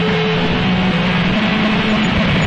噪声和尖叫声 " 噪声4
描述：采用Sond Forge 10 + VST FX制成
Tag: 机械 电子 噪音 外国人